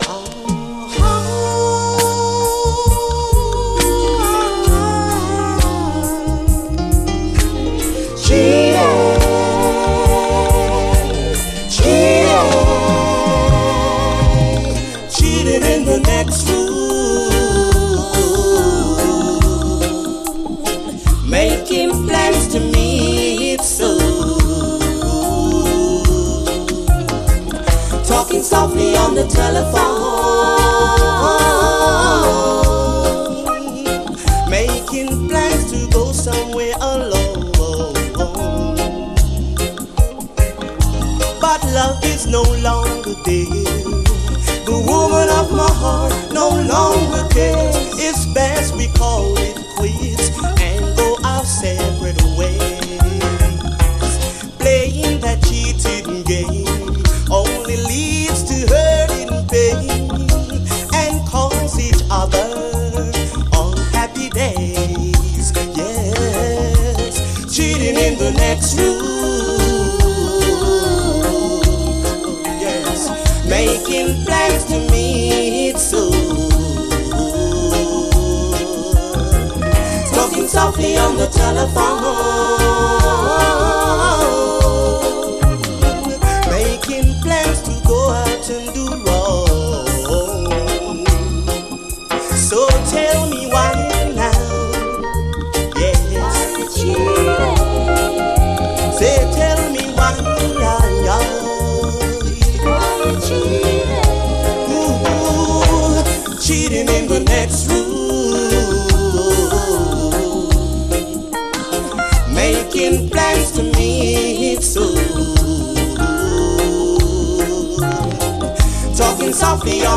REGGAE
女性ヴォーカル＆コーラスが素晴らしいキラー・チューン。